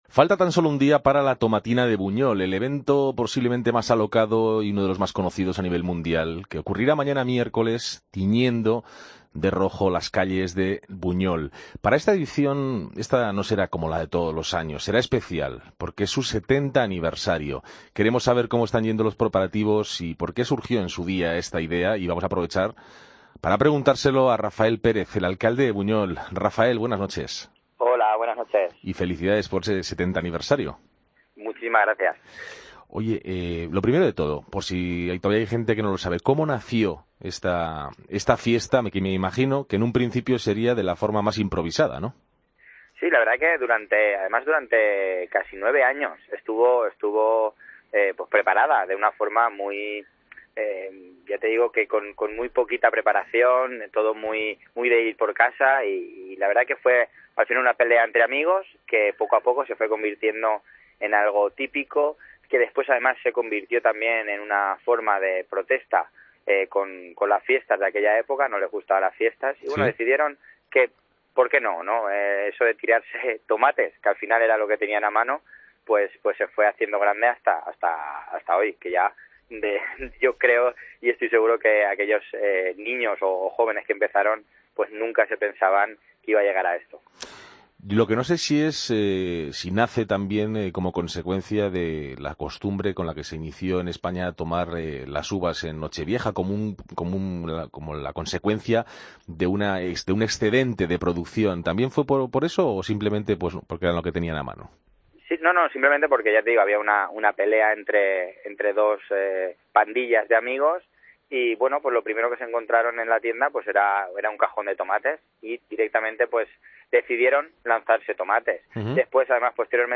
AUDIO: Charlamos con Rafael Pérez, alcalde de Buñol.